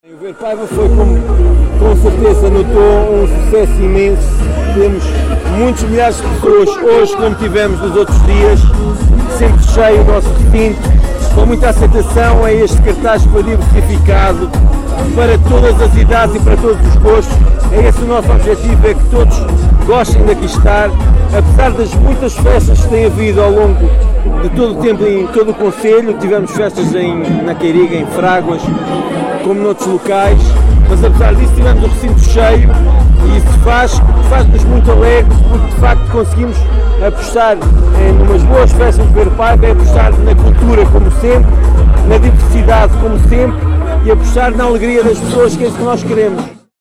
Paulo Marques, Presidente do Município de Vila Nova de Paiva, em declarações à Alive FM, disse que esta edição 2024 do Ver Paiva “foi um sucesso imenso”, “um cartaz diversificado para todas as idades e gostos“.